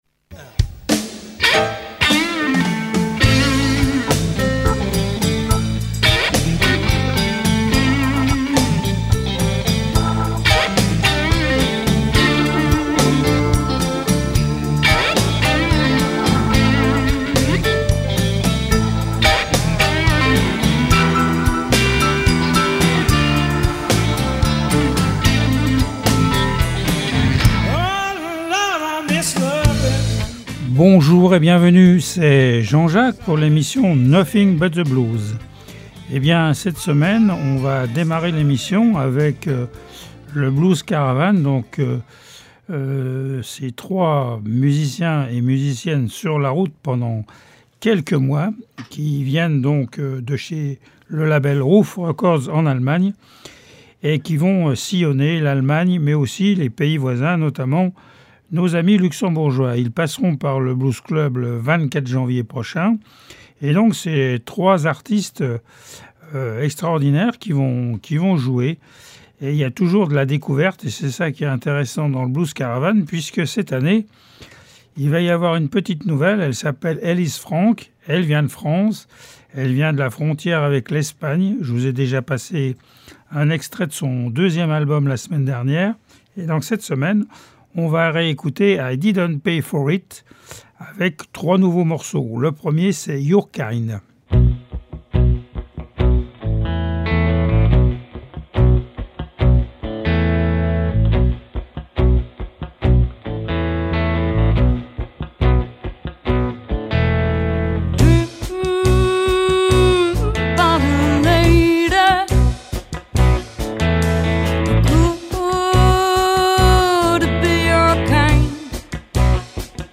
Du traditionnel au blues rock actuel.
L’émission offre un espace aux musiciens Lorrains et à la particularité de présenter de la musique en Live et des interviews.